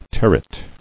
[ter·ret]